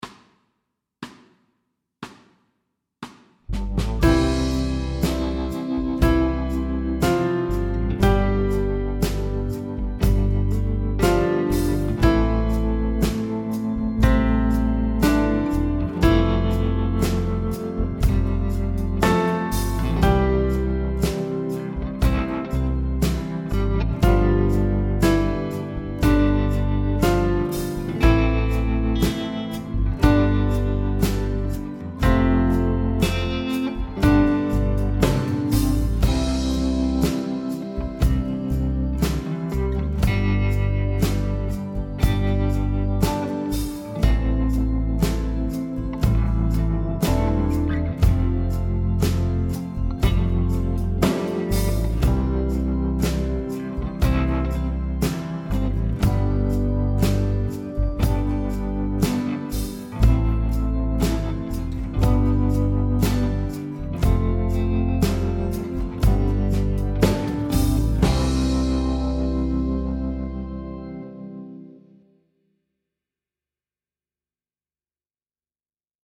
Slow C instr (demo)
Rytmeværdier: 1/1-, 1/2-, og 1/4 noder og pauser.